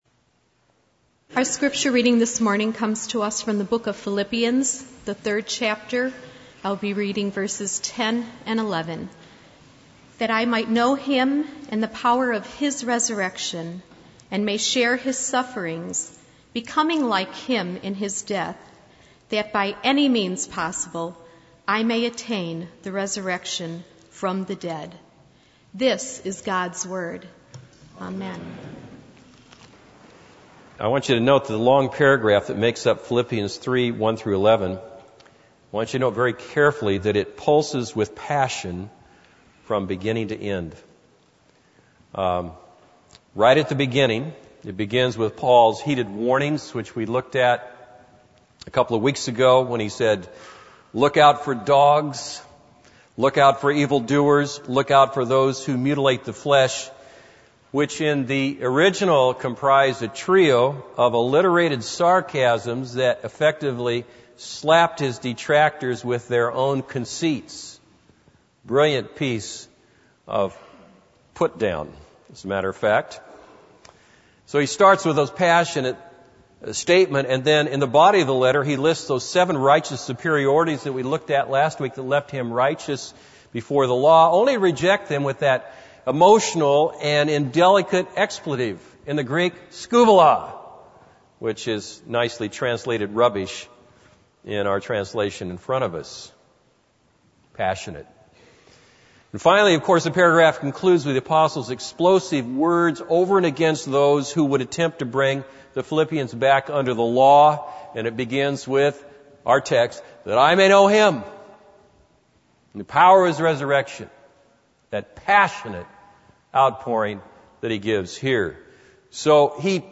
This is a sermon on Philippians 3:10-11.